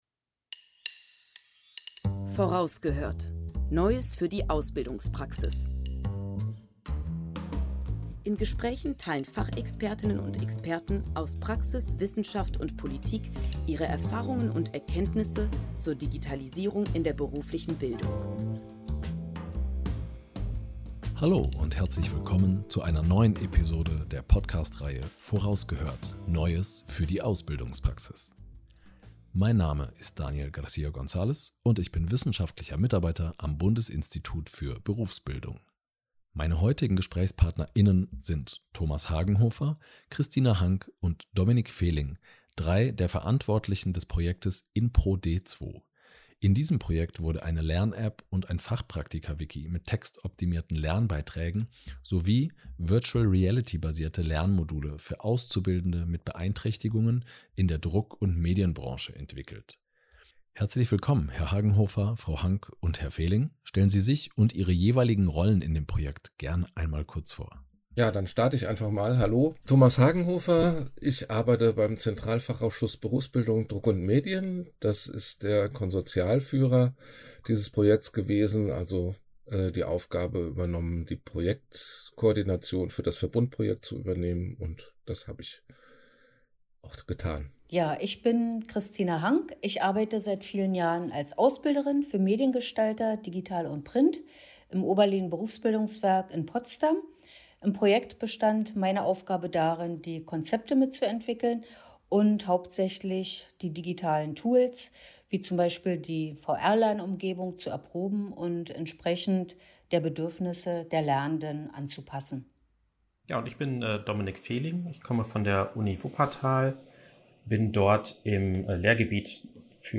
Das Projekt InProD² hat sich zum Ziel gesetzt, die inklusive Berufsausbildung in der Druck- und Medienwirtschaft individueller, einfacher, barrierefreier und digitaler zu gestalten. Im Gespräch geben die Verantwortlichen Einblicke in ihre Arbeitsergebnisse.